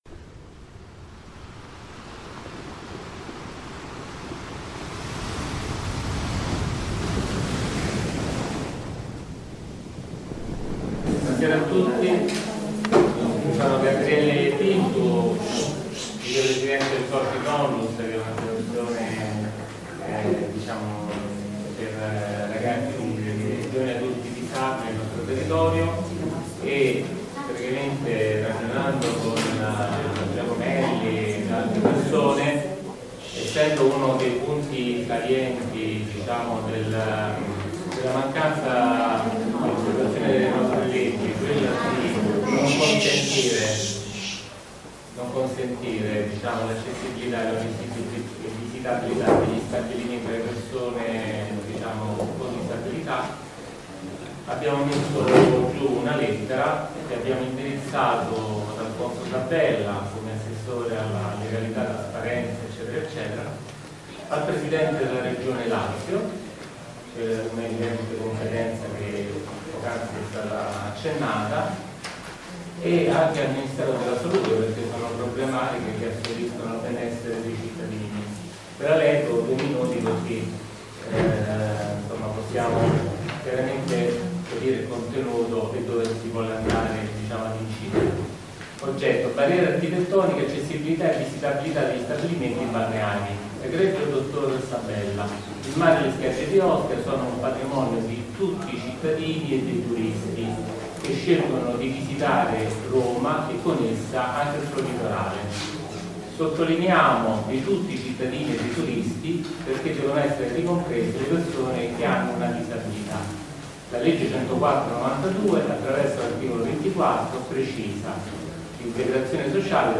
Assemblea annuale 2015 dell'Associazione Mare Libero, Ostia Lungomare Paolo Toscanelli, 186 - 17 ottobre 2015 - Roma.